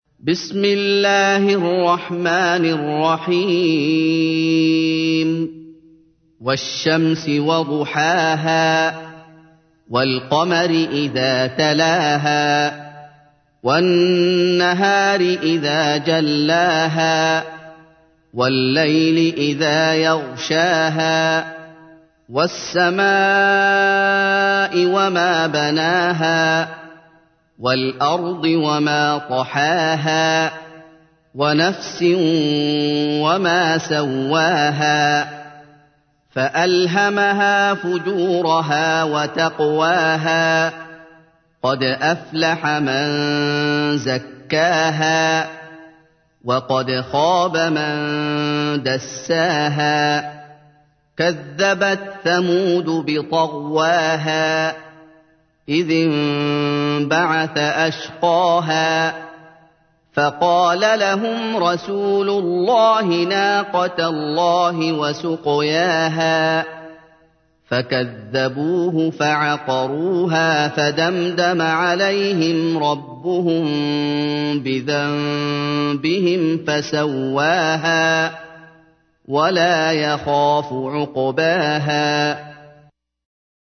تحميل : 91. سورة الشمس / القارئ محمد أيوب / القرآن الكريم / موقع يا حسين